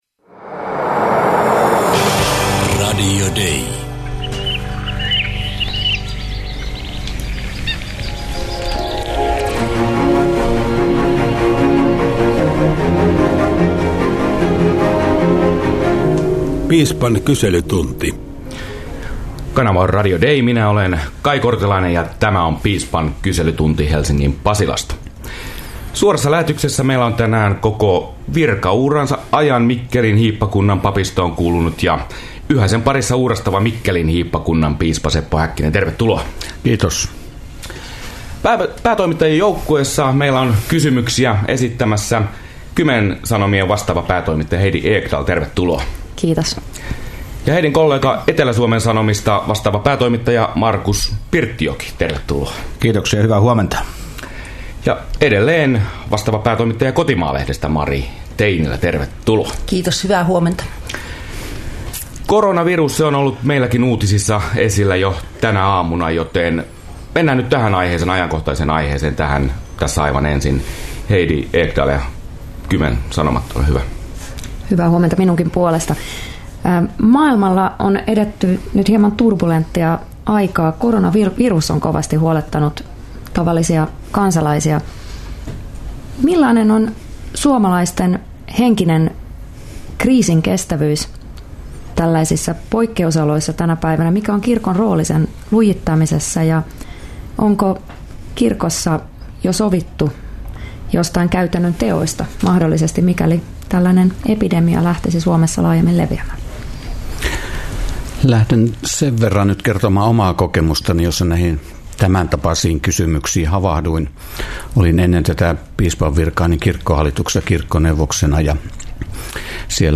KUUNTELE: Päätoimittajat tenttaavat suorassa lähetyksessä Mikkelin piispa Seppo Häkkistä - Kristityt Yhdessä ry
Keskiviikkoaamuna suoraan lähetykseen Piispan kyselytunnille toimittajien kysymyksiin saapuu vastaamaan Mikkelin hiippakunnan piispa Seppo Häkkinen.